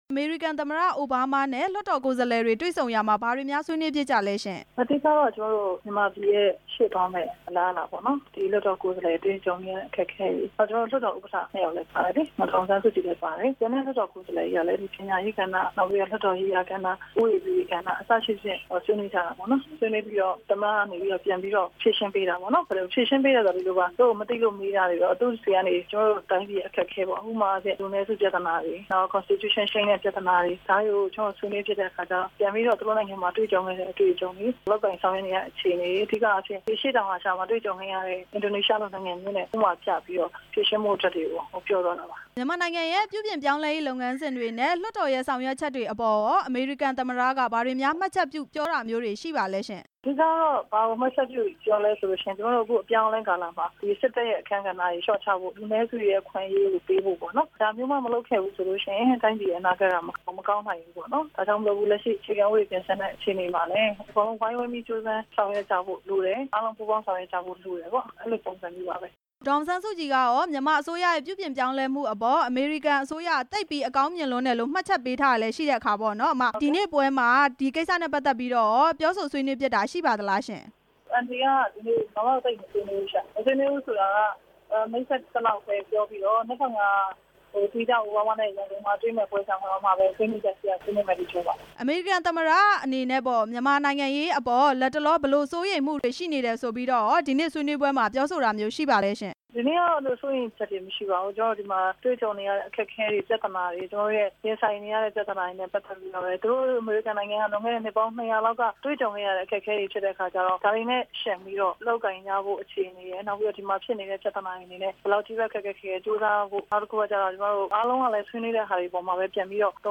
လွှတ်တော်ကိုယ်စားလှယ် ဒေါ်ဖြူဖြူသင်းကို မေးမြန်းချက်